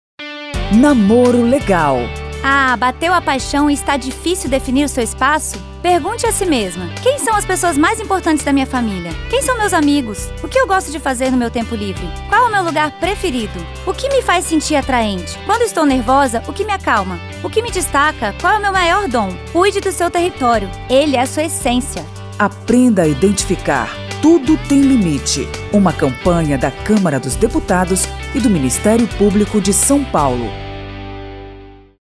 São dezoito spots que falam sobre relacionamentos tóxicos.